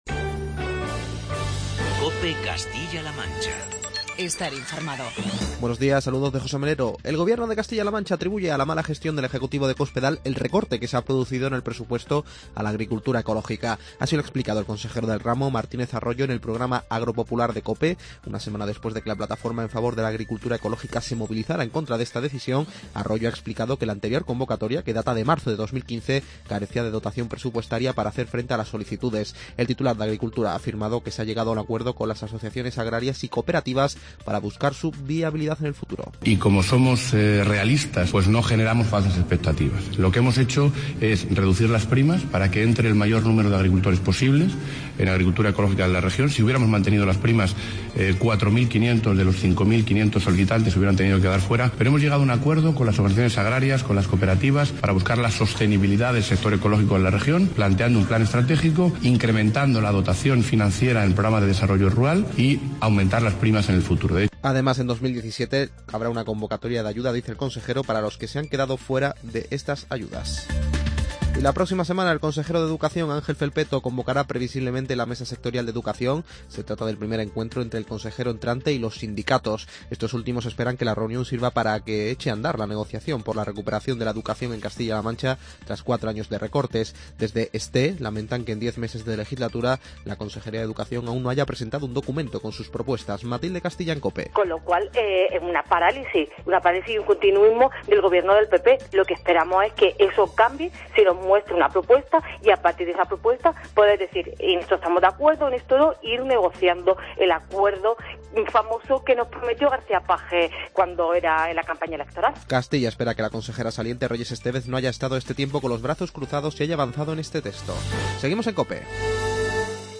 Informativo regional
El consejero de Agricultura, Martínez Arroyo, ha pasado por los micrófonos de "Agropopular" en COPE.